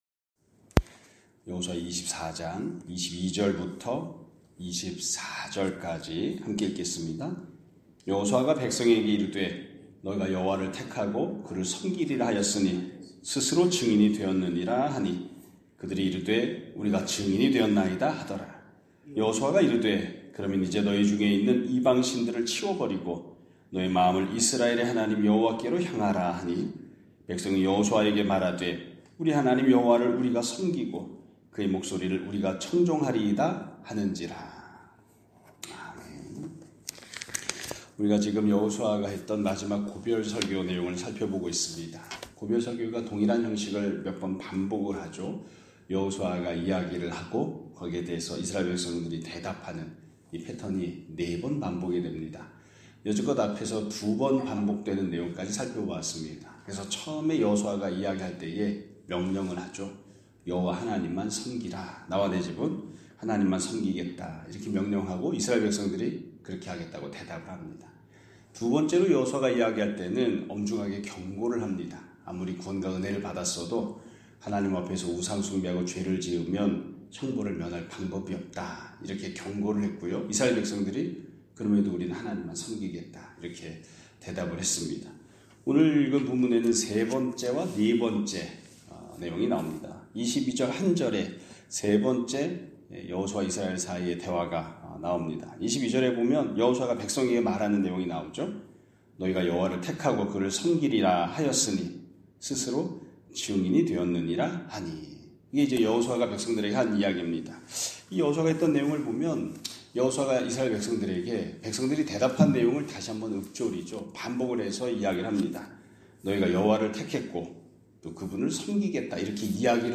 2025년 3월 4일(화 요일) <아침예배> 설교입니다.